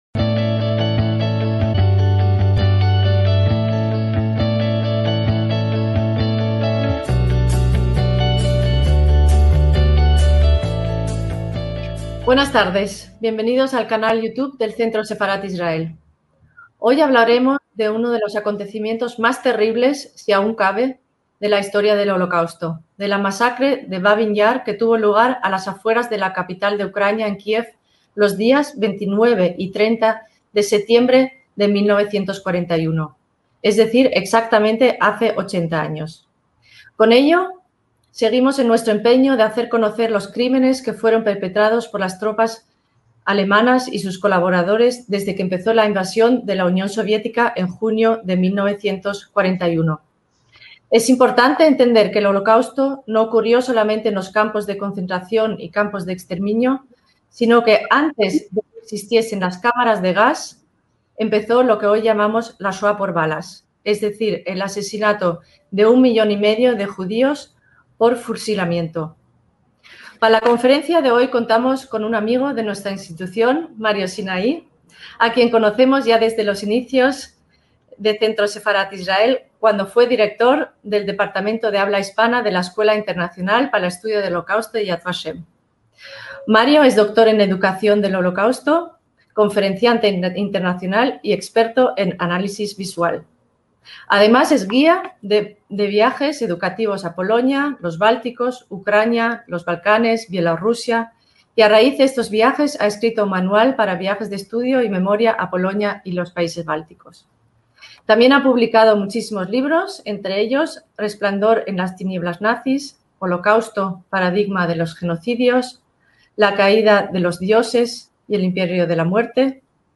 ACTOS "EN DIRECTO" - En esta conferencia telemática sobre el Holocausto en Ucrania se realizará un viaje por los principales centros judíos del país: Kiev, Lviv y Odesa, así como las ciudades de Berditchev, Zithomir, Rovno, Brody, Zhovkva, Dohobrych, Chernovitz, Madzibush y Vynitza.